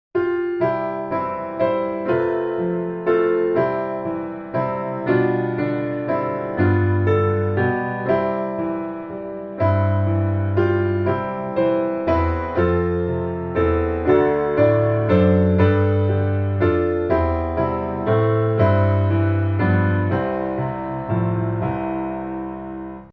C Majeur